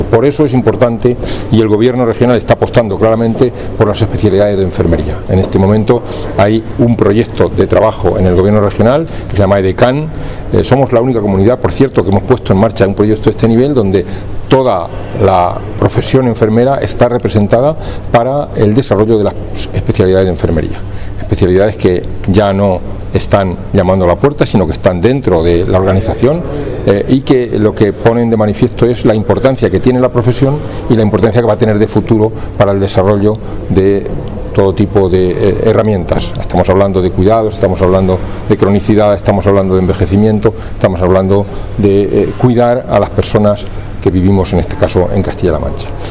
Estamos hablando de cuidados, de cronicidad, de envejecimiento, de cuidar a las personas con las que vivimos, en este caso en Castilla La Mancha”, ha expuesto el consejero de Sanidad, Jesús Fernández Sanz, en la ‘Jornada a debate’ de ANDE Castilla-La Mancha. fernandez_sanz_edecam.mp3 Descargar: Descargar